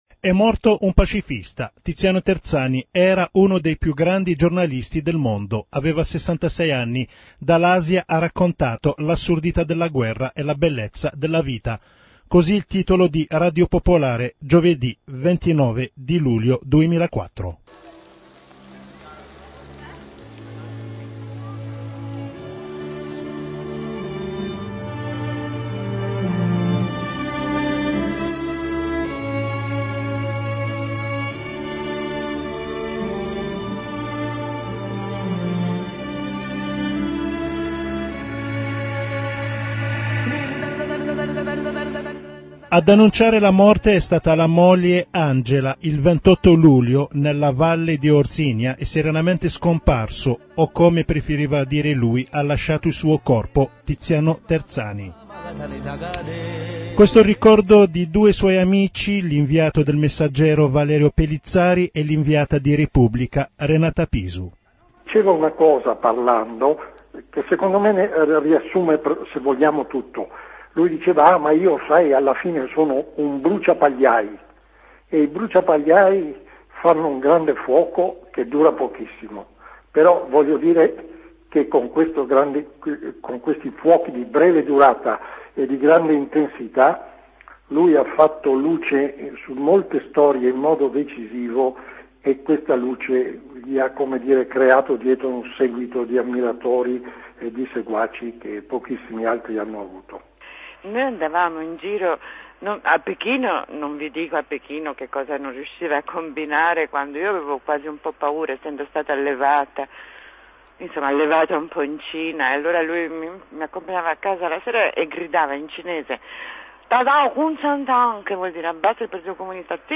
[ITA - Audiolibro] - Tiziano Terzani_completa.mp3